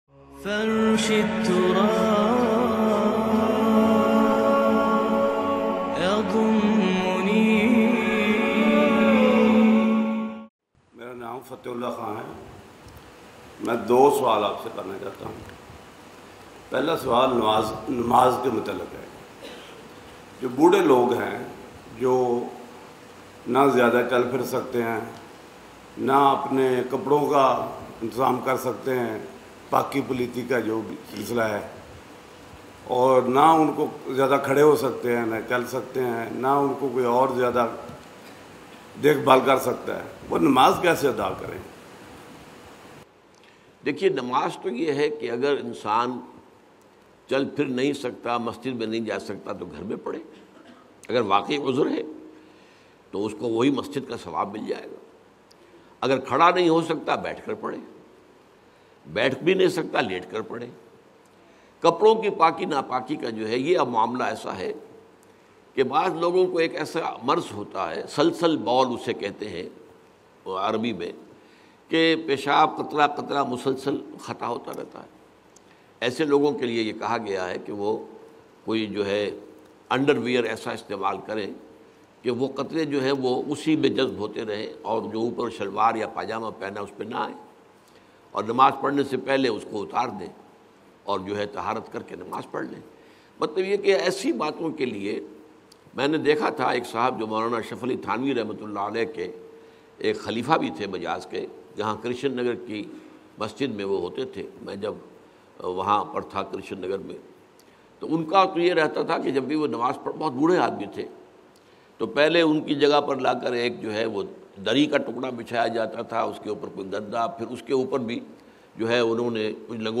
Numaz Ka Tarika By Dr Israr Ahmed Bayan MP3 Download